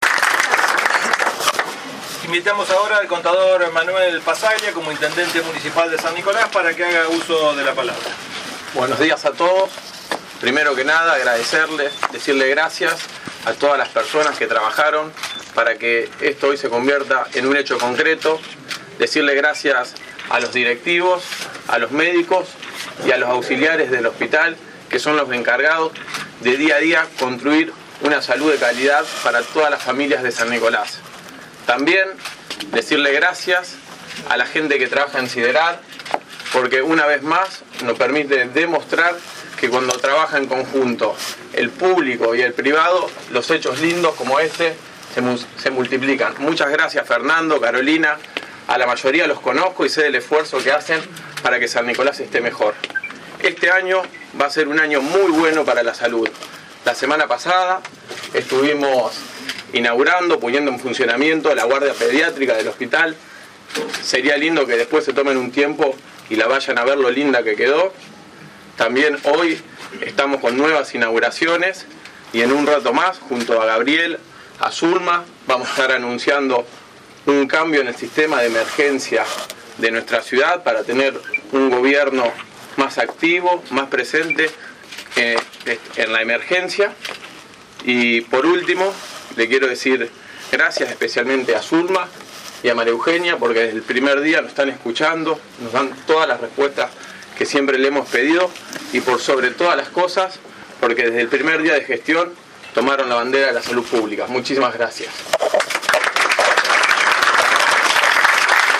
Se realizó un descubrimiento de placa y recorrida de las instalaciones con motivo de la finalización de las obras de puesta en valor del Servicio de Clínica y de Cirugía del Hospital, llevadas a cabo gracias al aporte solidario de la Maratón 10K Ternium realizada en octubre del año pasado.
Audio: El Ite. interino, Cdor. Manuel Passaglia